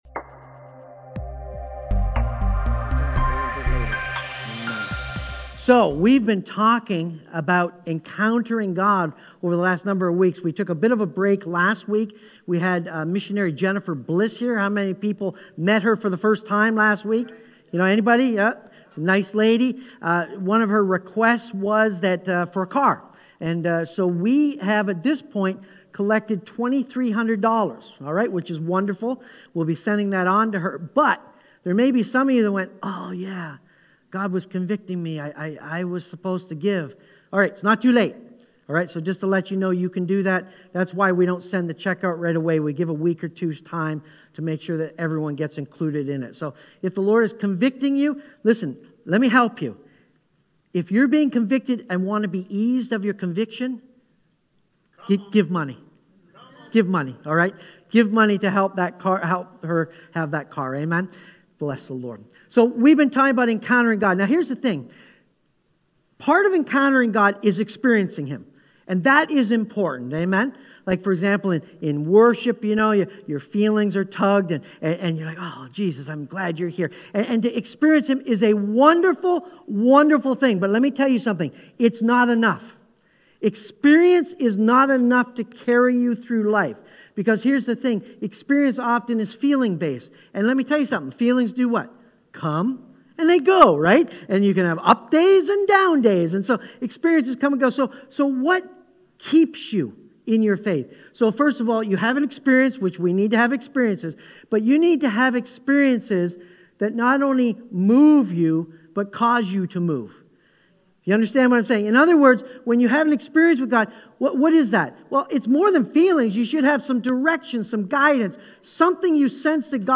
Or browse our sermon archives on YouTube or Facebook.